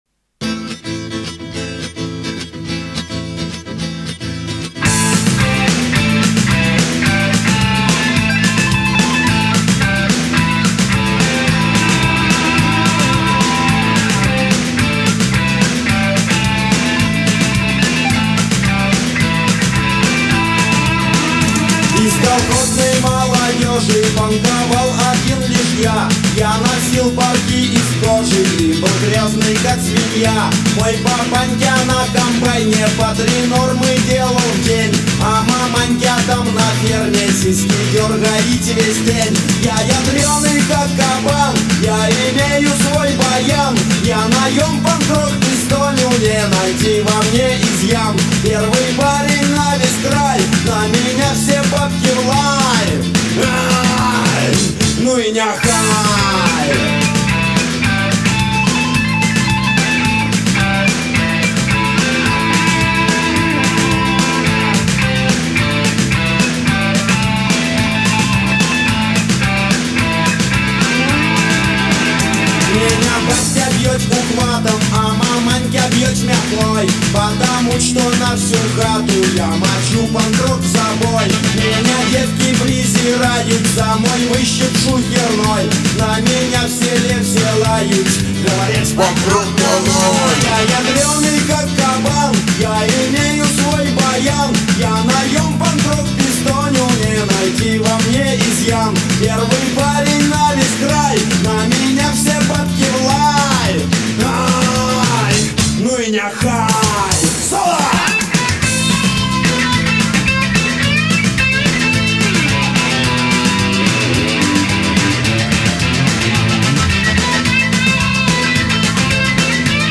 Жанр: Punk Rock